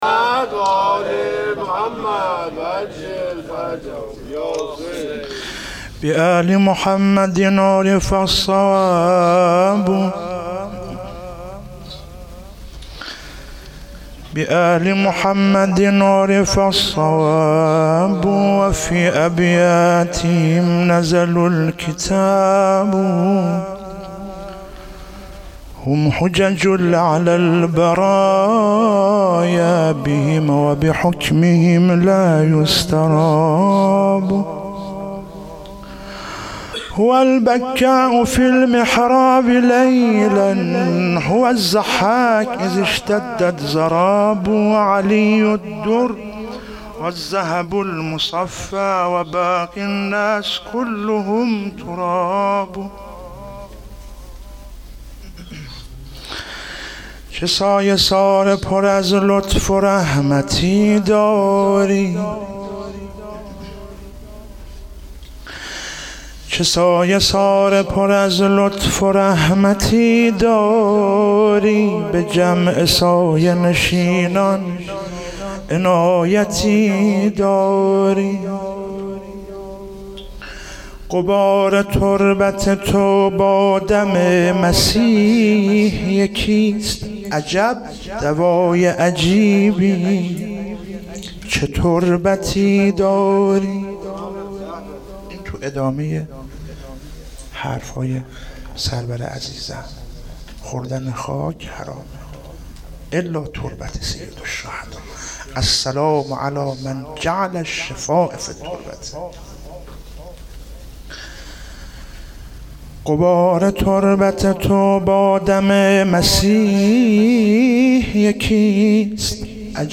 میلاد سرداران کربلا 97 - مدح - چه سایه سار پر از لطف رحمتی داری
ولادت سرداران کربلا